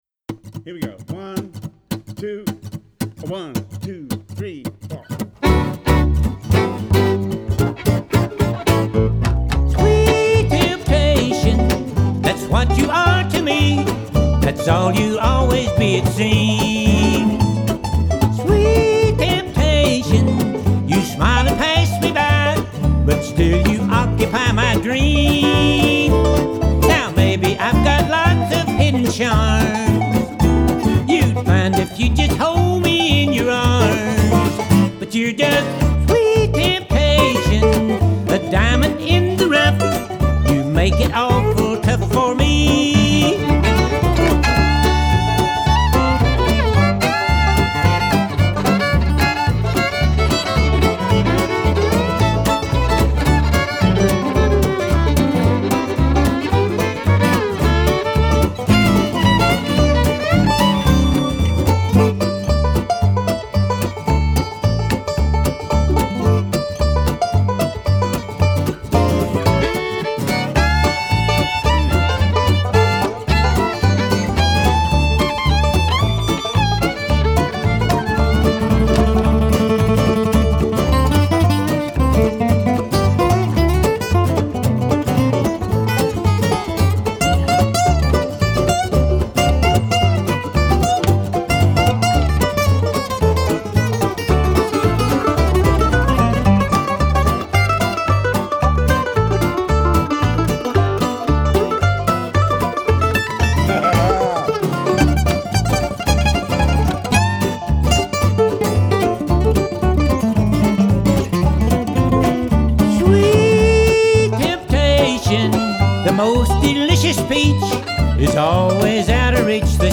Hi-Res Stereo
Genre : Classic